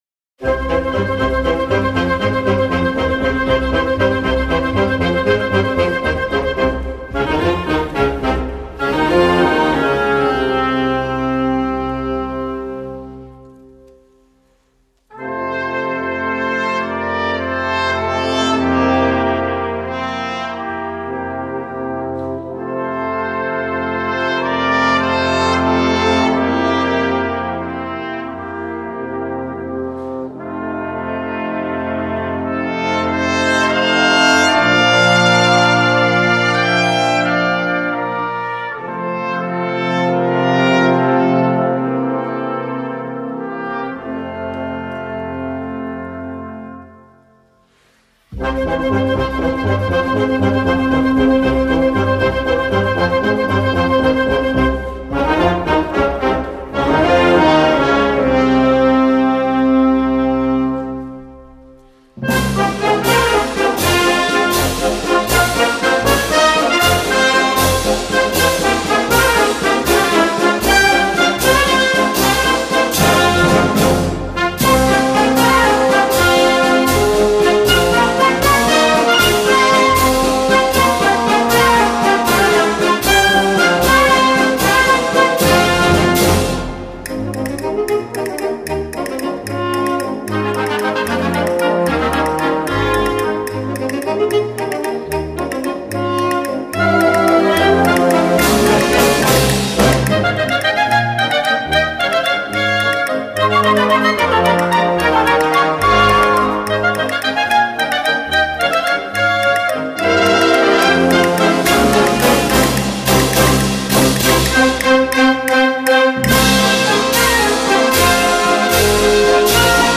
Gattung: Valse Espagnole
Besetzung: Blasorchester